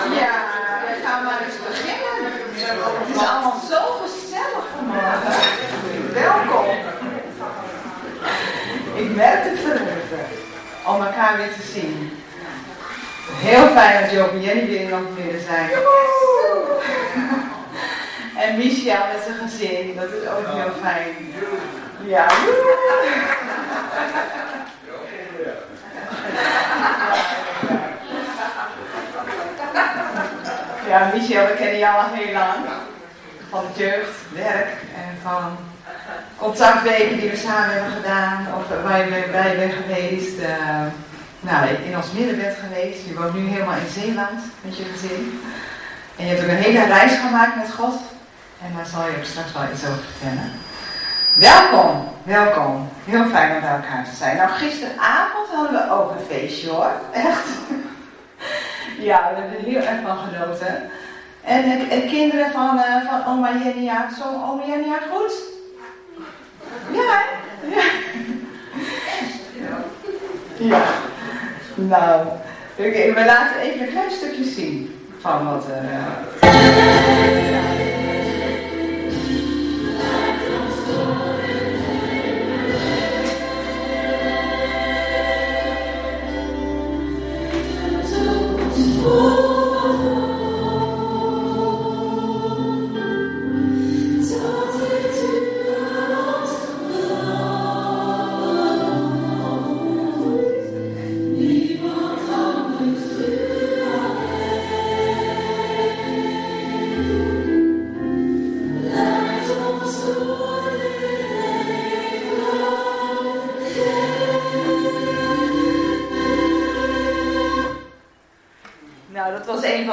22 maart 2026 dienst - Volle Evangelie Gemeente Enschede